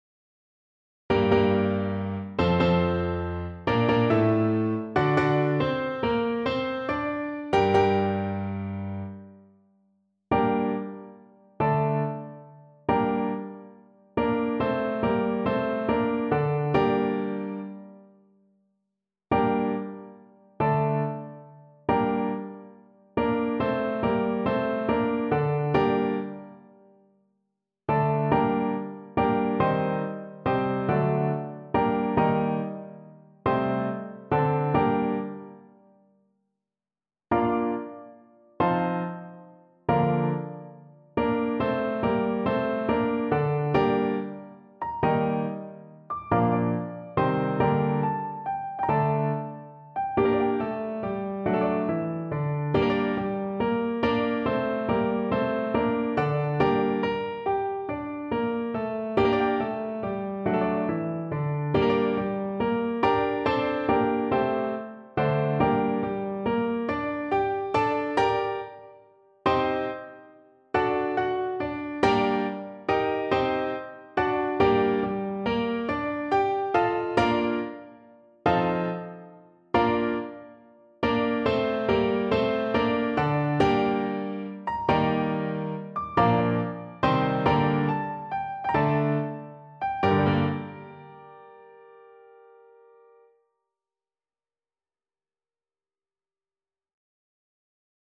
Traditional Charles Stanford Awake, Awake, Fianna (Irish Folk Song) Flute version
Play (or use space bar on your keyboard) Pause Music Playalong - Piano Accompaniment Playalong Band Accompaniment not yet available transpose reset tempo print settings full screen
Flute
9/8 (View more 9/8 Music)
G minor (Sounding Pitch) (View more G minor Music for Flute )
Lento maestoso